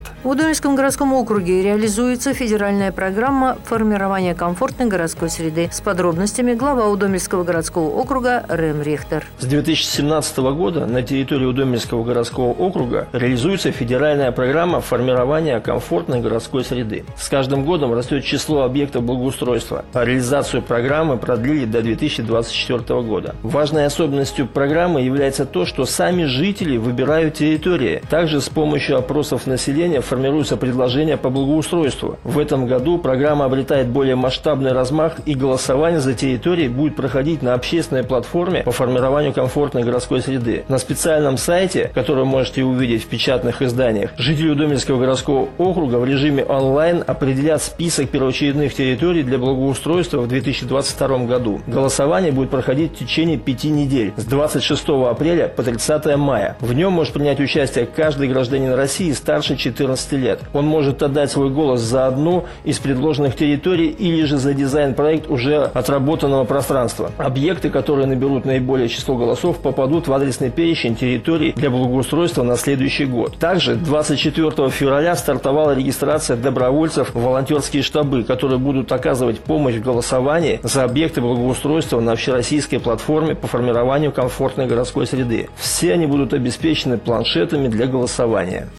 Глава Удомельского городского округа в новостном выпуске «Авторадио. Удомля» рассказал о реализации федеральной программы «Формирование комфортной городской среды», а также о подготовке к рейтинговому голосованию по выбору общественной территории для благоустройства в 2022 году на общероссийской платформе по формированию комфортной городской среды.